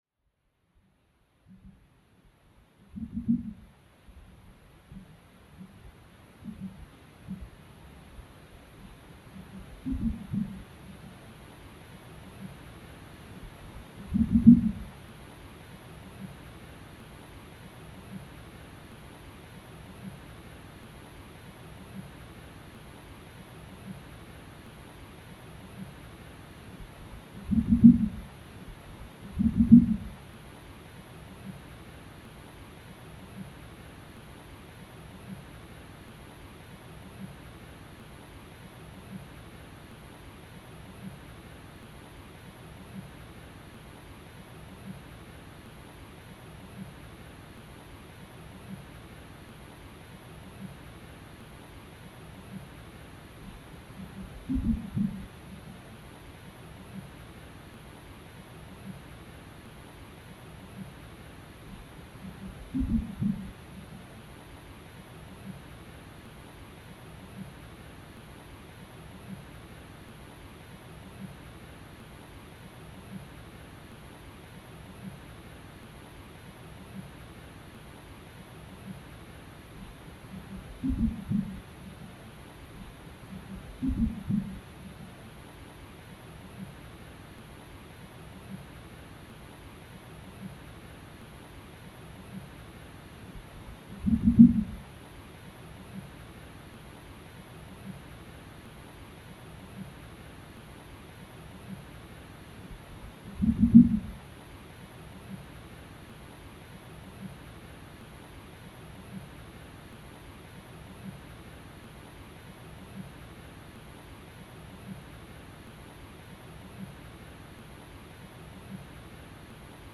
La traccia audio riporta due tipologie di suono prodotte dalla lastra metallica, ottenute durante la fase di lavorazione dell’opera.
Il suono evoca l’immagine fotografica di partenza, spostando la percezione dal piano visivo a quello sonoro, alterandone la struttura nel tempo per farla diventare altro.